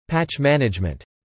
pæʧ-ˈmænɪʤmənt)